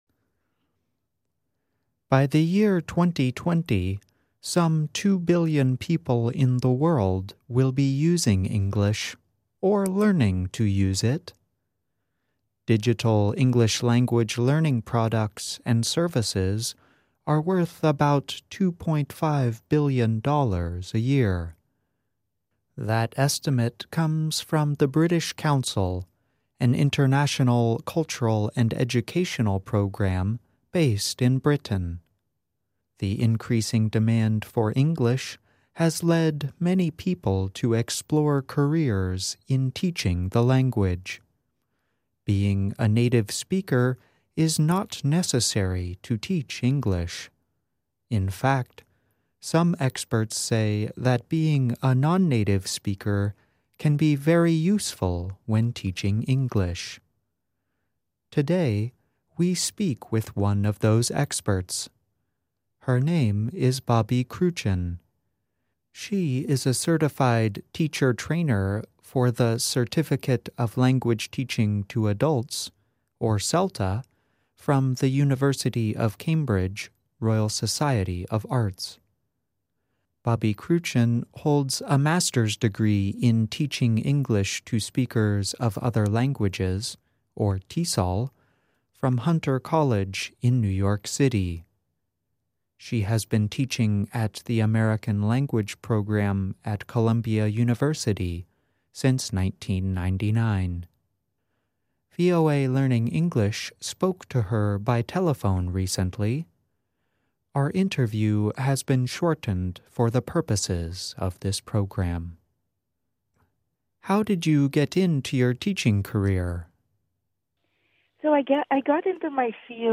VOA Learning English spoke to her by telephone recently.